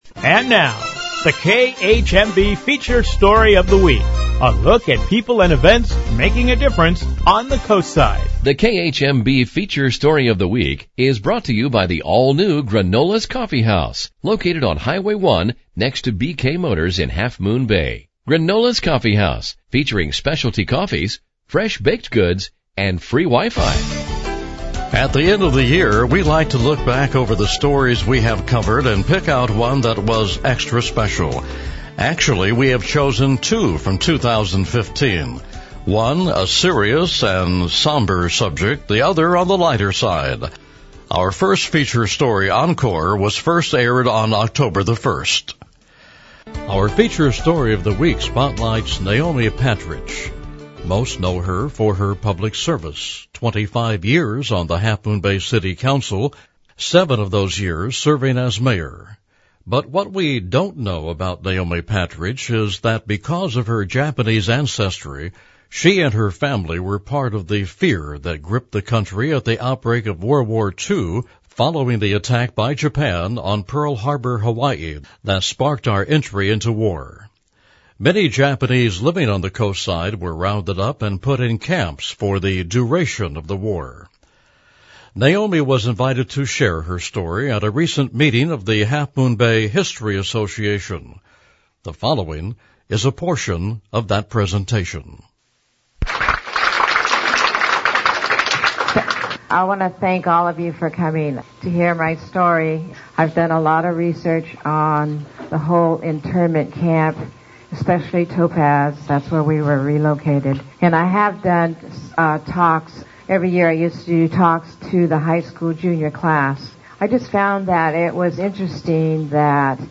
A poignant recording of Naomi Patridge, a Half Moon Bay public figure, sharing her experience during World War Two as a Japanese-American. Naomi recently told her story about being in Japanese internment camps during WWII to the Half Moon Bay Historical Association. She shares the little known Japanese history of Pescadero, where Naomi lived before being moved into the internment camp at Tanforan Race Track in 1942, and her experience throughout the rest of the war.